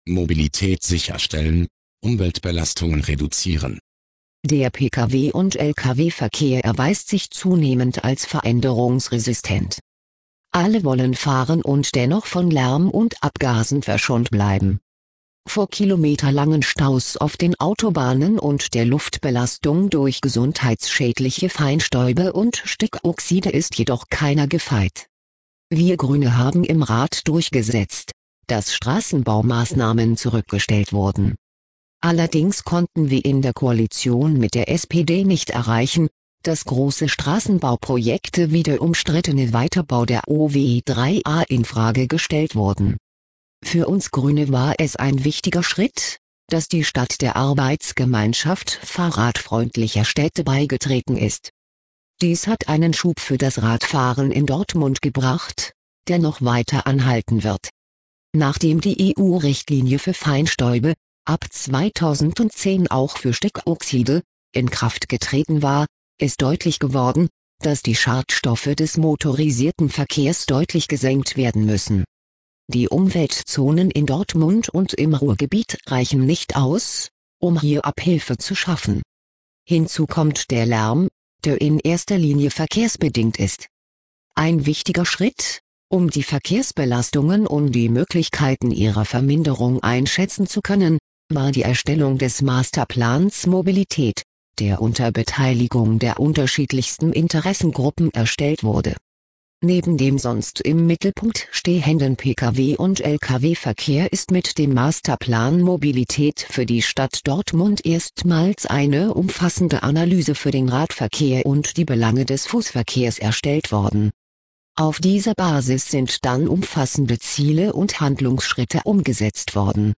Auszüge aus unserem Kommunalwahlprogramm 2009 als Sprachversion